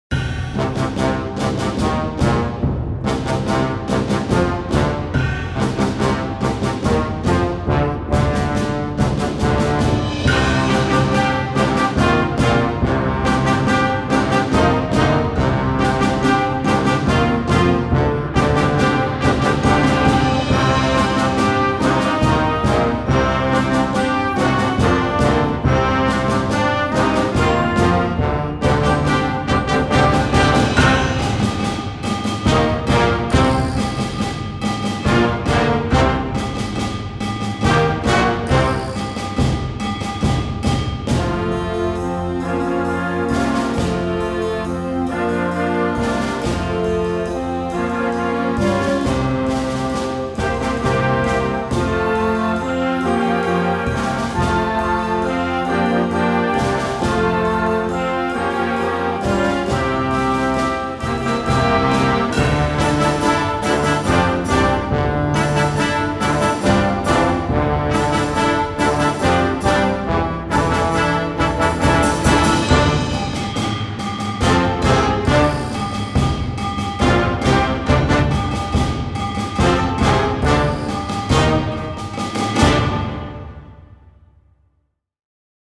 Gattung: Konzertwerk für Jugendblasorchester
Besetzung: Blasorchester
Umwerfend, lehrreich und episch!